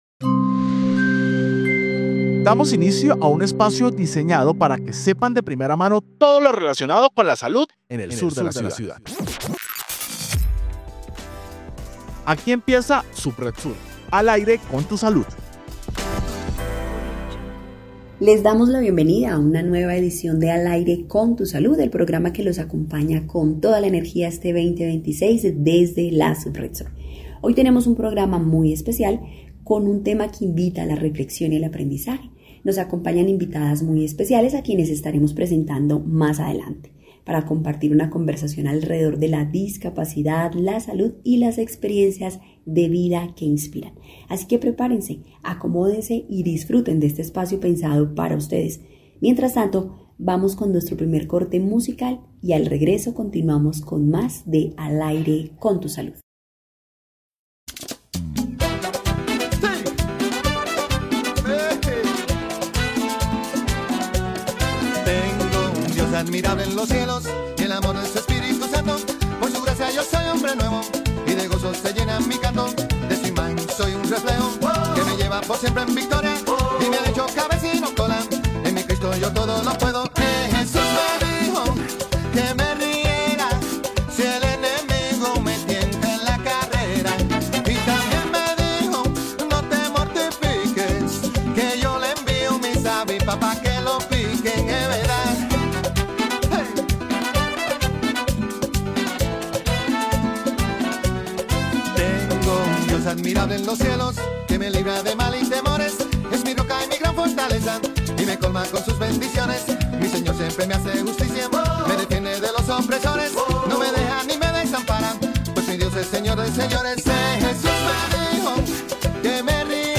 Programa Radial Subred Sur: Al Aire con la Salud